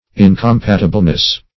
Search Result for " incompatibleness" : The Collaborative International Dictionary of English v.0.48: Incompatibleness \In`com*pat"i*ble*ness\, n. The quality or state of being incompatible; incompatibility.
incompatibleness.mp3